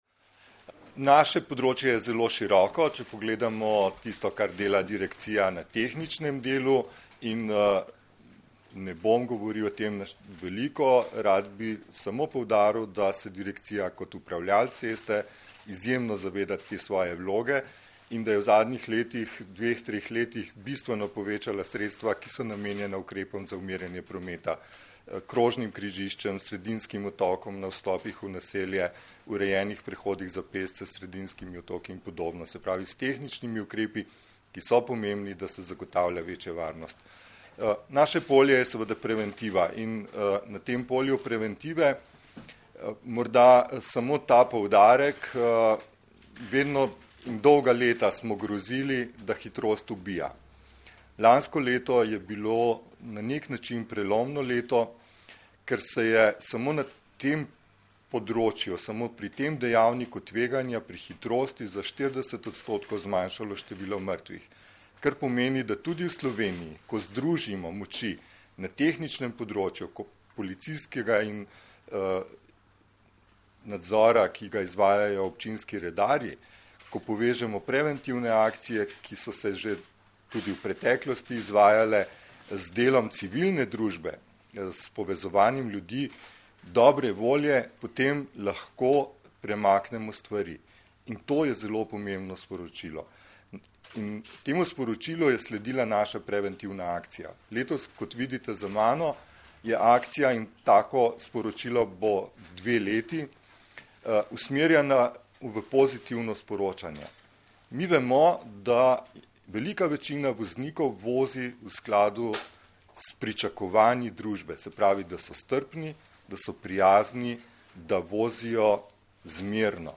Policija - Policisti začeli s poostrenim nadzorom hitrosti - preventivna kampanja Hvala, ker voziš zmerno - informacija z novinarske konference
Zvočni posnetek izjave